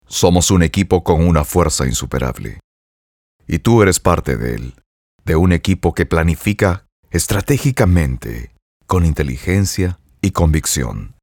Professional Spanish Latin American Neutral • Conversational • Friendly • Natural • Commercials • Documentaries • Corporate Narrations.
Sprechprobe: Werbung (Muttersprache):
Full time Professional Voice Over Artist. As a Native Spanish Male Voice Talent, has a wide range of vocal skills